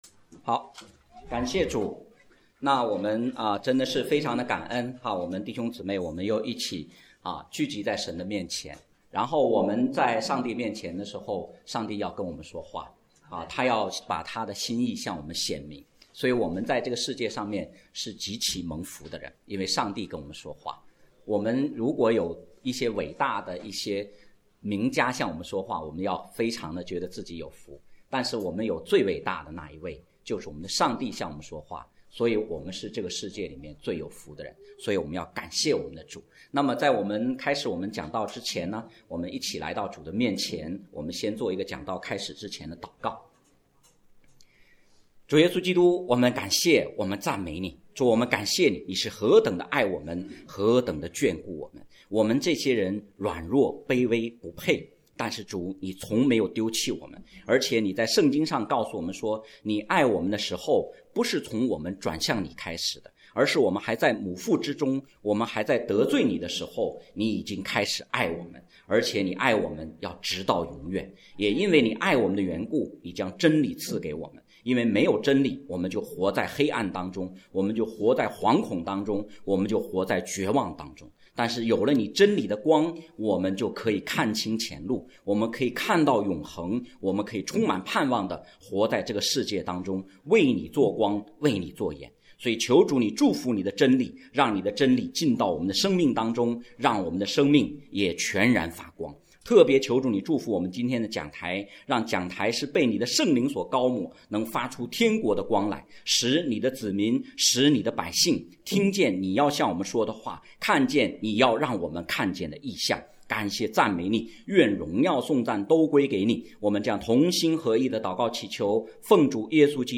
》 讲道录音 点击音频媒体前面的小三角“►”就可以播放 了。